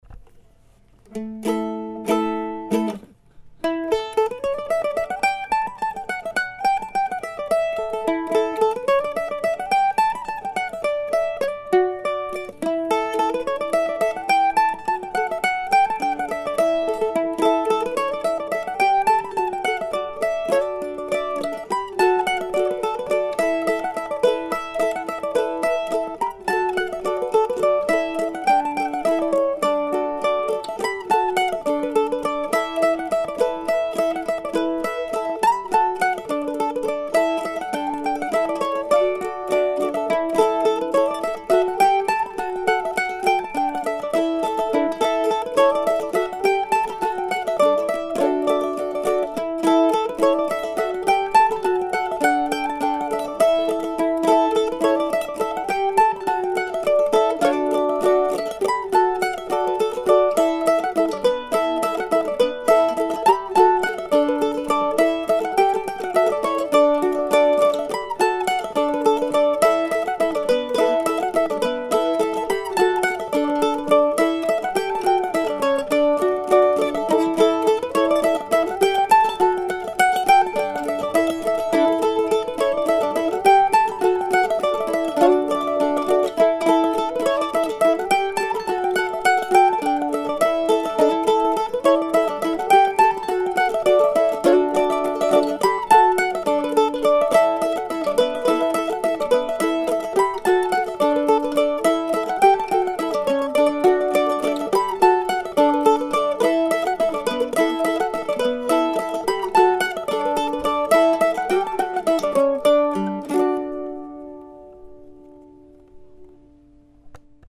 I recorded it while still fresh using my old black Gibson A model, melody first and then the backup.